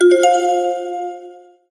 飽きの来ないシンプルな通知音。